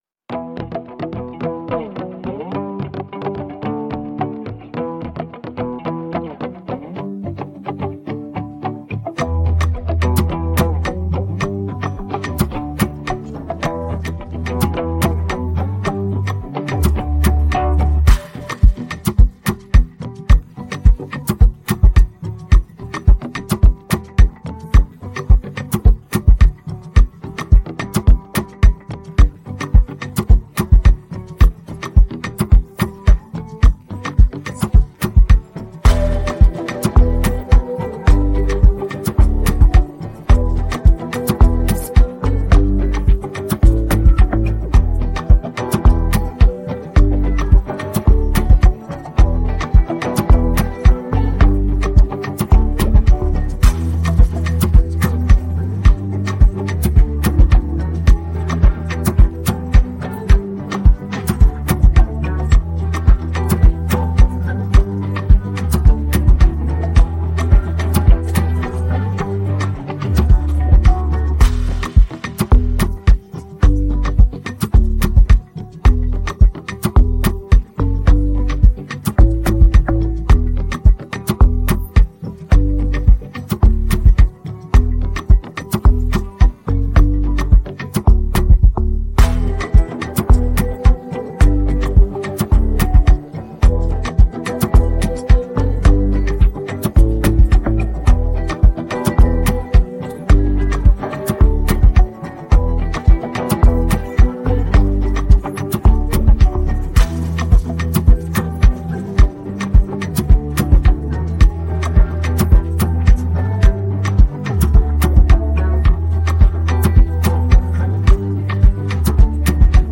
it’s a cool and emotional afrobeat to bounce on.